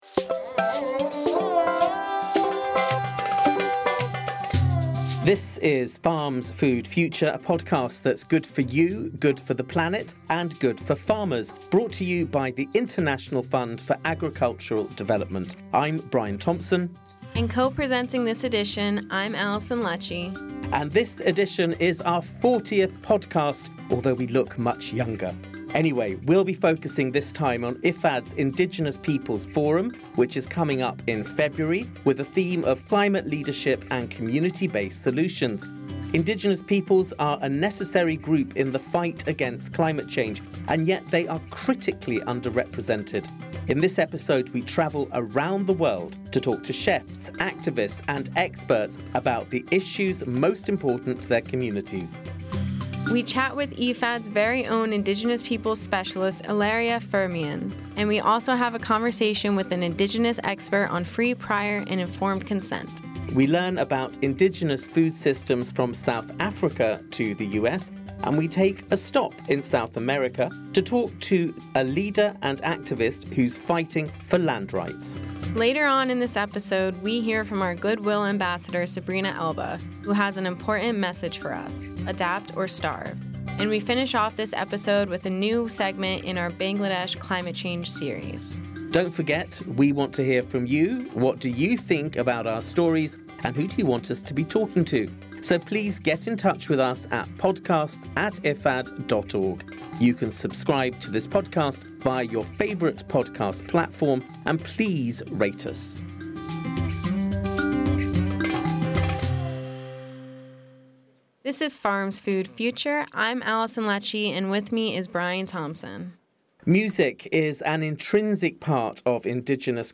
then a discussion on gender and land rights, climate change and food with indigenous chefs, activists, and experts.